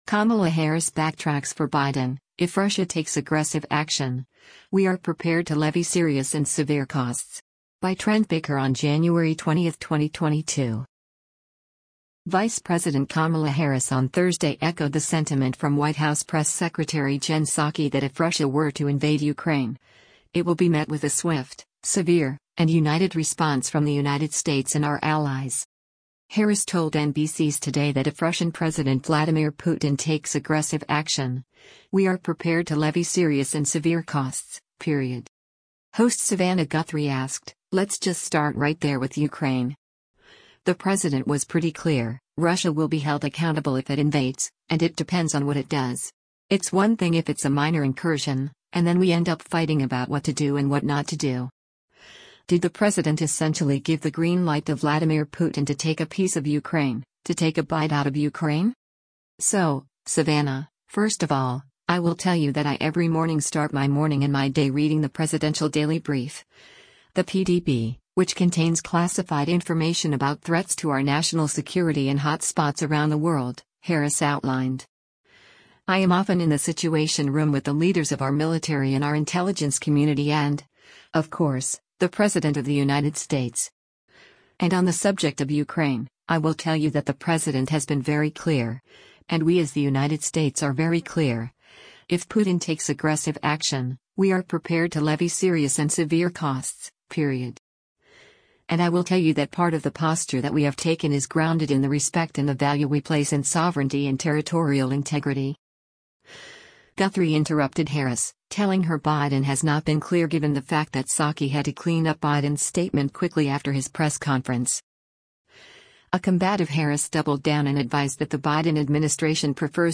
Harris told NBC’s “Today” that if Russian President Vladimir Putin “takes aggressive action, we are prepared to levy serious and severe costs, period.”
Guthrie interrupted Harris, telling her Biden has not been “clear” given the fact that Psaki had to clean up Biden’s statement quickly after his press conference.
A combative Harris doubled down and advised that the Biden administration prefers to fix the tensions “diplomatically.”